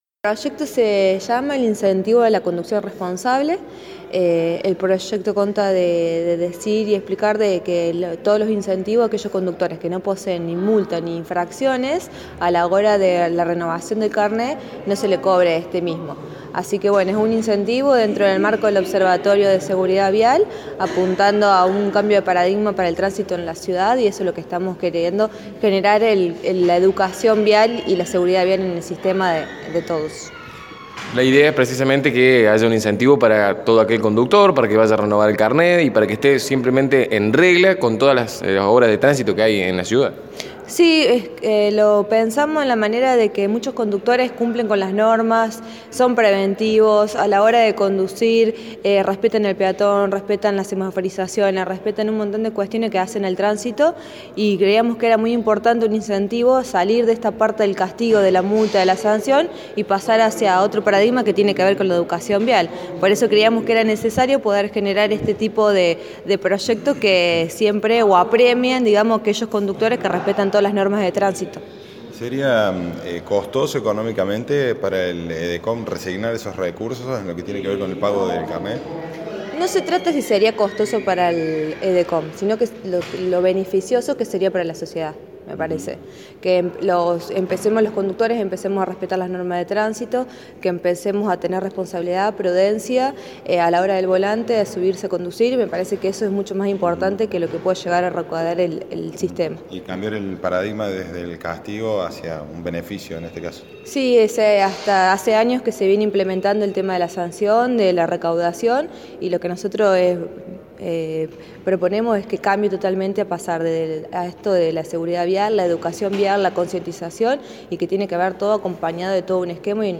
La edil así detalla su iniciativa: